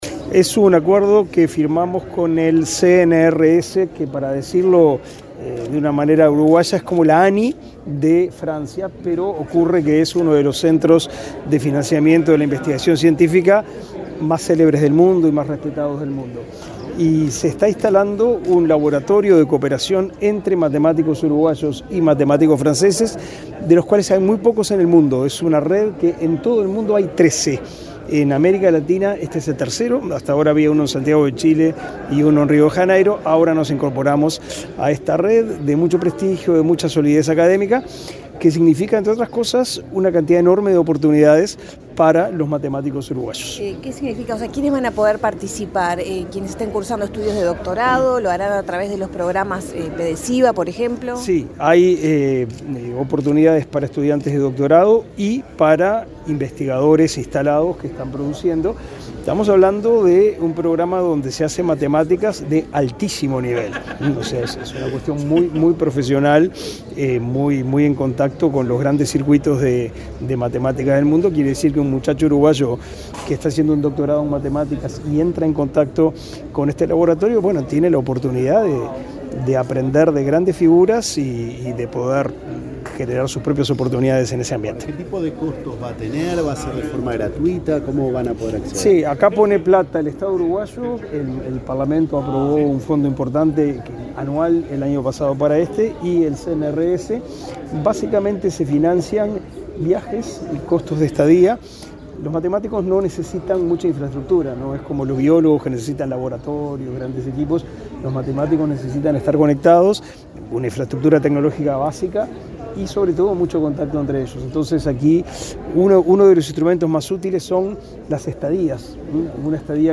Declaraciones del ministro de Educación y Cultura, Pablo da Silveira